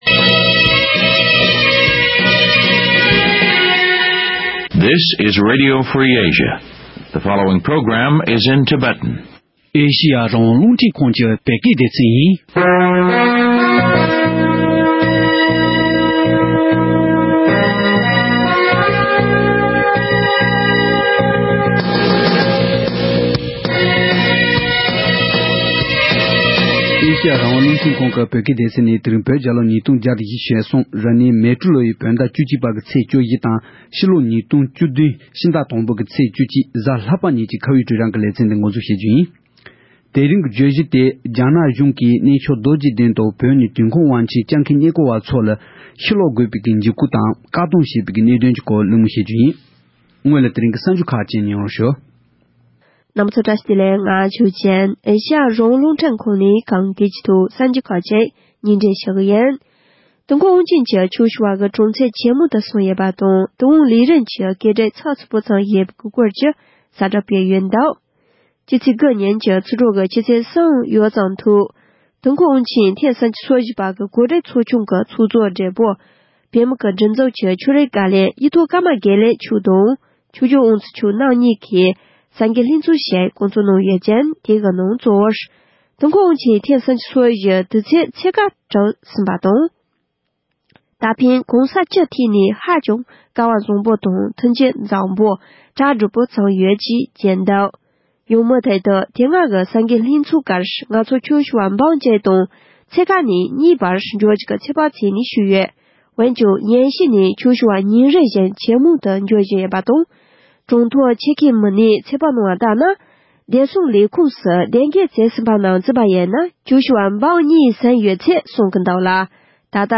གླིང་མོལ་ཞུས་པ་ཞིག་གསན་རོགས་གནང་།